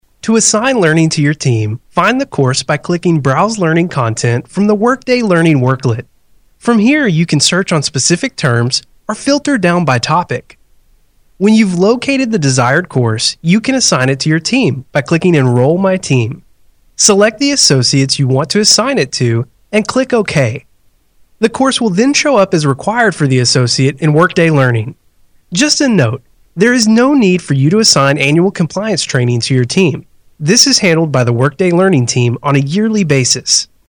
男英125
男英125 美式英语 略年轻 课件 解说 产品介绍 积极向上|时尚活力|亲切甜美|素人